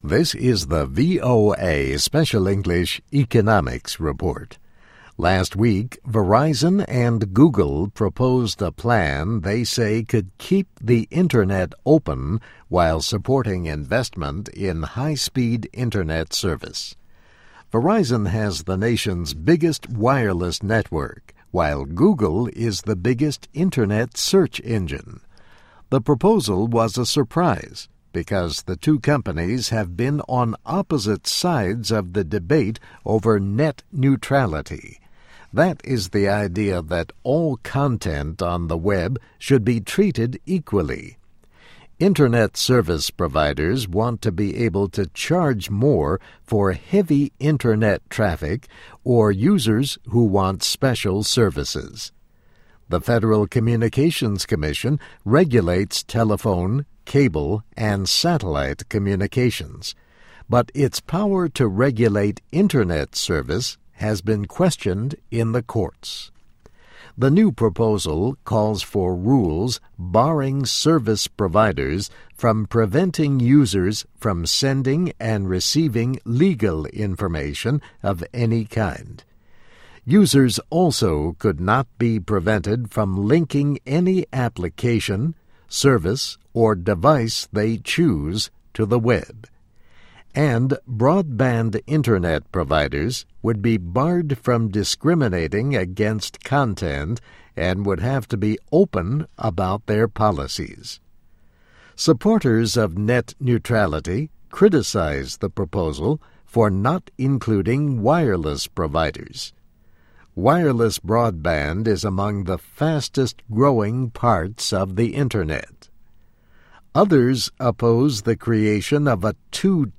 VOA Special English - Text & MP3